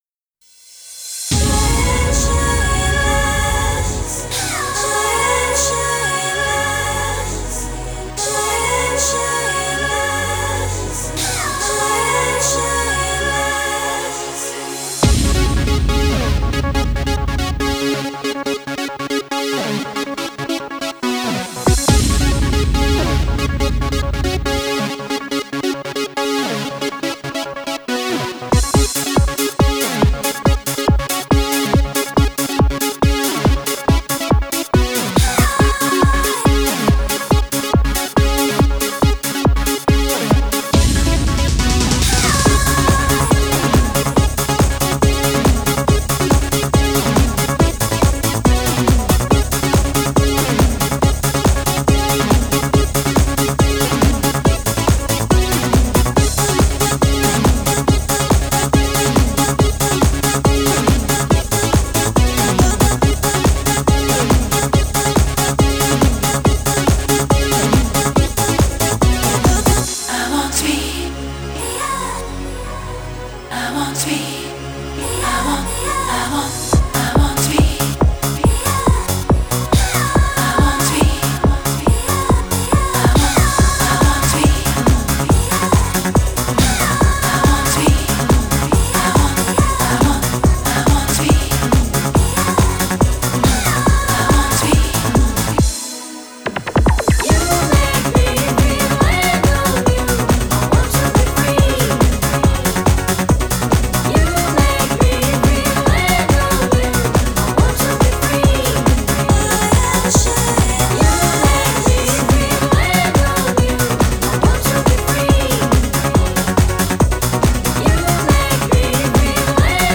Genre: Italodance.